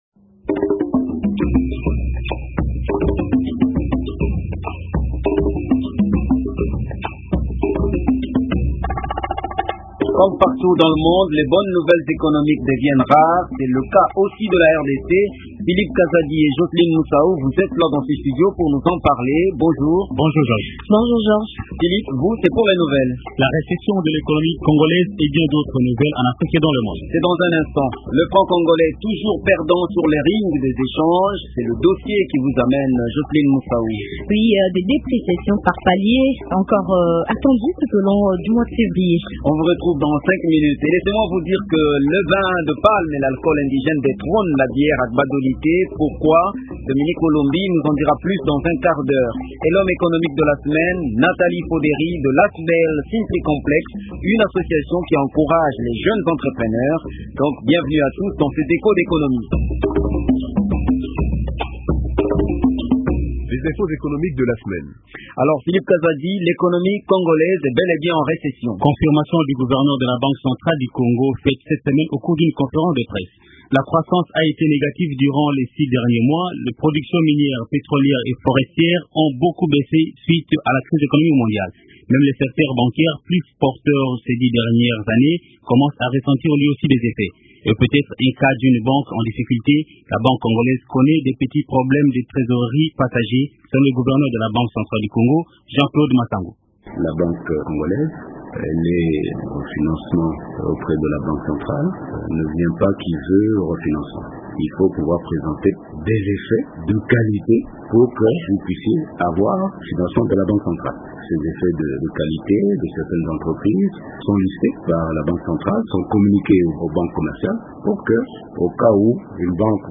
Le magazine économique de Radio Okapi sacrifie 6 minutes à ce dossier. Le reportage économique de la semaine vous conduit a Gbado, ville de l’Equateur où le vin de palme détrône la bière.